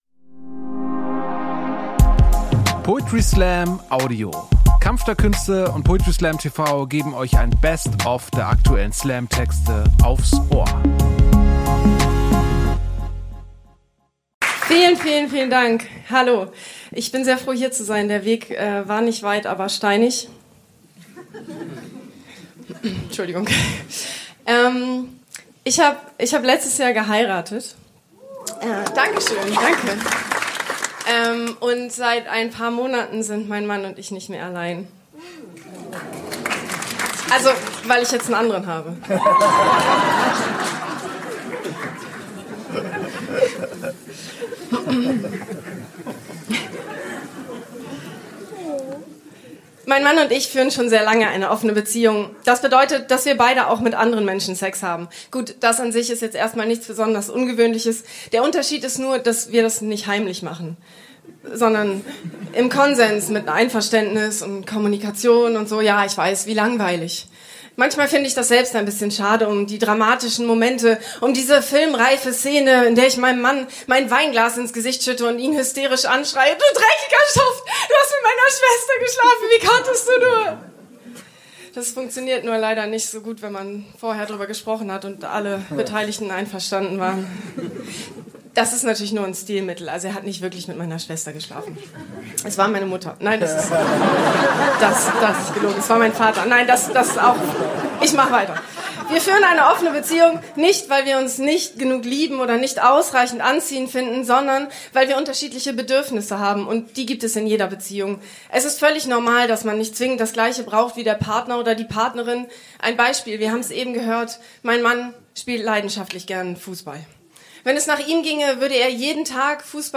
Clips, Podcasts, Specials, Audio, Video, Bild & Ton!Wir bündeln das alles, zünden ein Feuerwerk an Spoken Word, Stories, Lyrik, Satire und Comedy und knallen die Rakete aus unserer Homebase in Hamburg in die weiten Himmel des Internets.